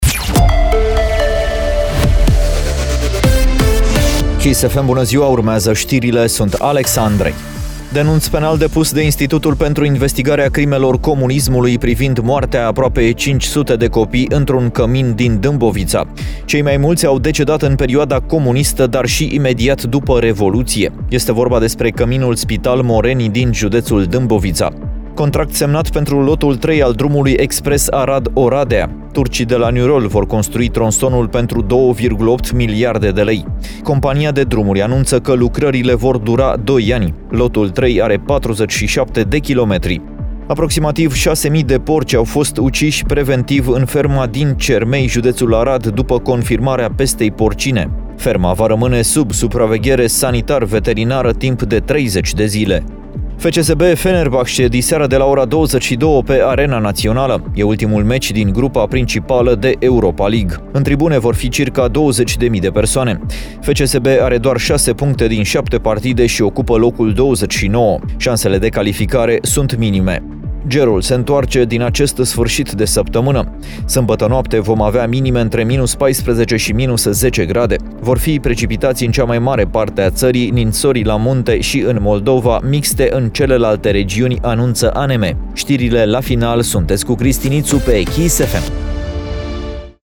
Știrile zilei de la Kiss FM - Știrile zilei de la Kiss FM